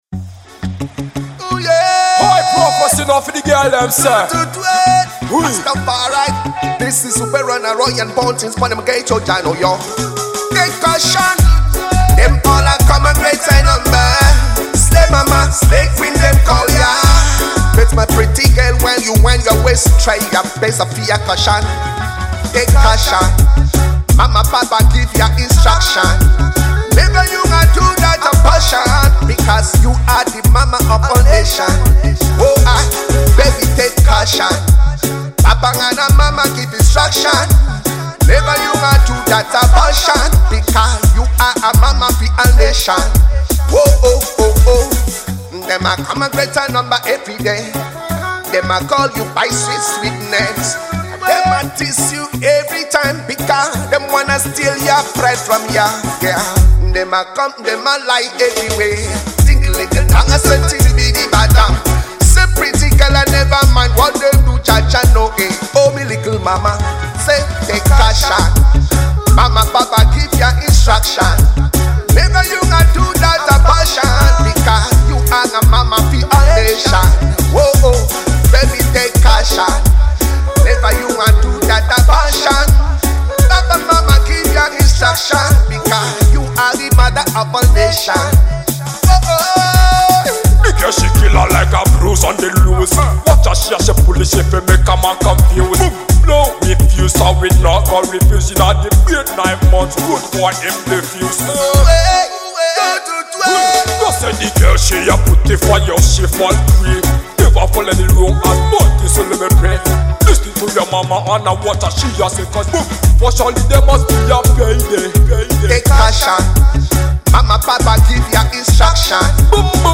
raggae dancehall
sensational positive vibes